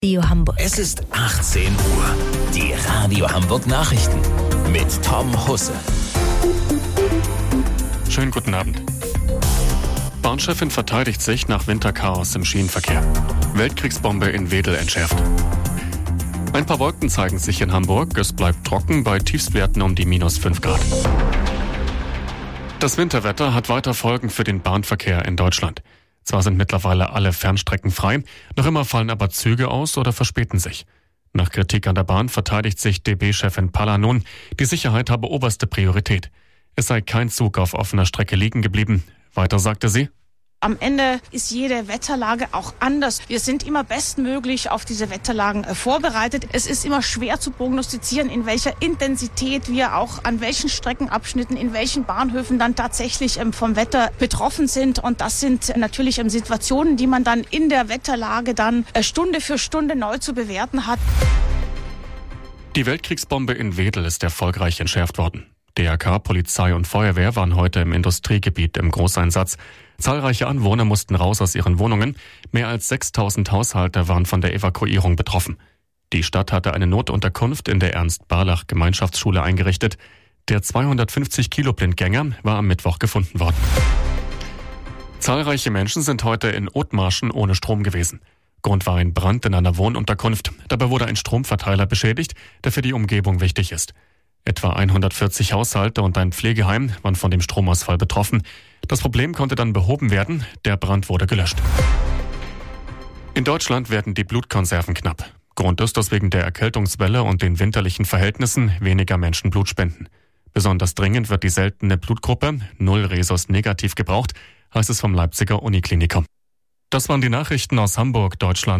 Radio Hamburg Nachrichten vom 11.01.2026 um 18 Uhr